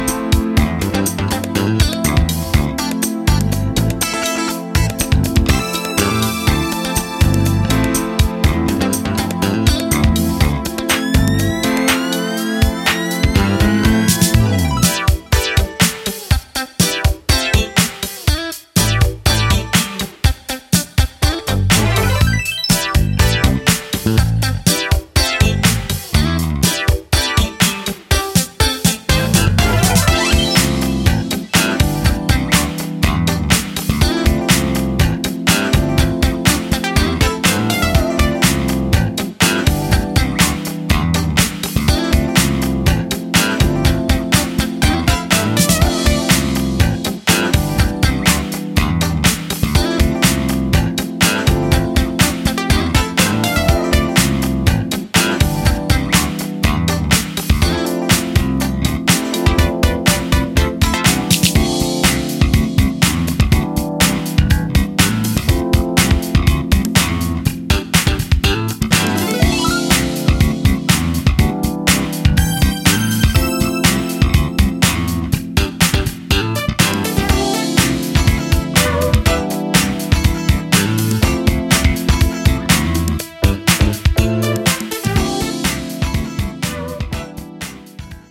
supplier of essential dance music